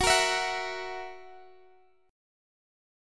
Listen to F#7 strummed